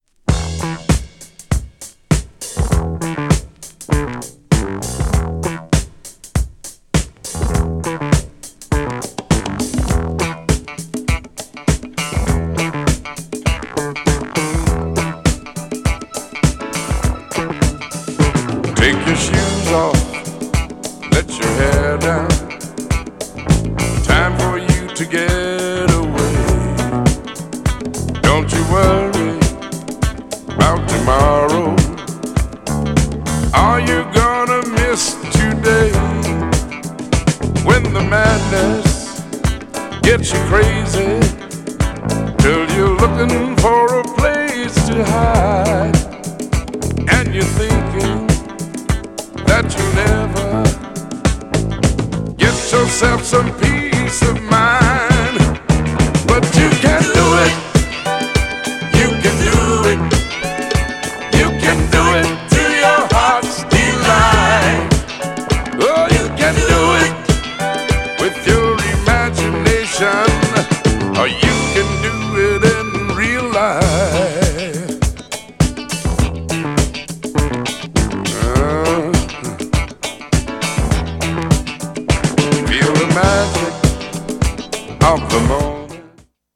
重厚なミディアムのA面とフリーソウルなB面どちらも最高!!
GENRE Dance Classic
BPM 106〜110BPM